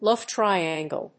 /ˈlʌv ˌtrʌɪæŋɡəl(米国英語)/